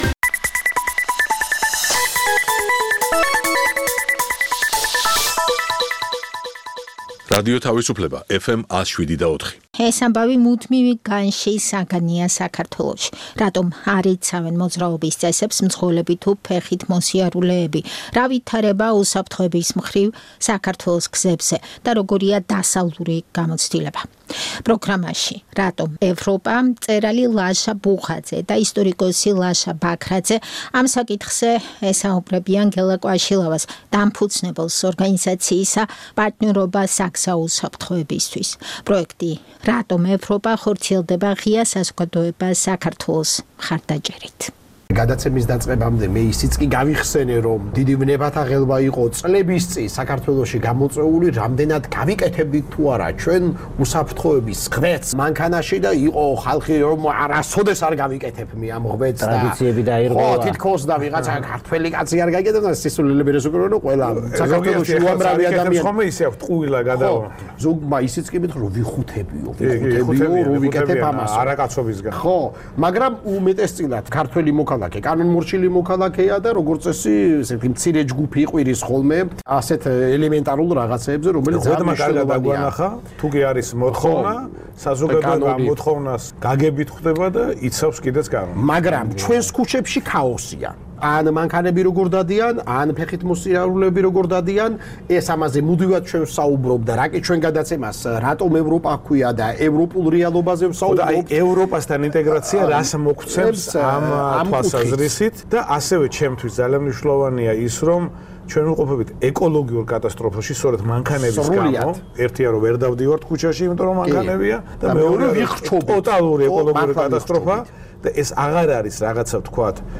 ყოველკვირეული გადაცემის მიზანია, კიდევ ერთხელ დააფიქროს მსმენელი დასავლური ღირებილებების თაობაზე, იმაზე, თუ რას ნიშნავს საქართველოში ევროპული არჩევანი. გადაცემას ორი ლაშა უძღვება - მწერალი ლაშა ბუღაძე და ისტორიკოსი ლაშა ბაქრაძე. გადაცემა ორშაბათობით 19:05-ზე გადის რადიო თავისუფლების ეთერში.